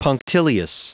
Prononciation du mot punctilious en anglais (fichier audio)
Prononciation du mot : punctilious
punctilious.wav